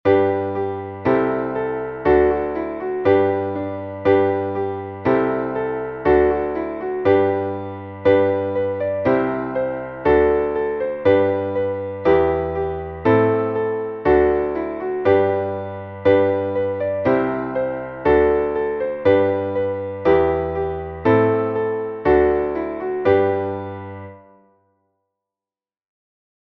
Traditionelles Kinderlied / Volkslied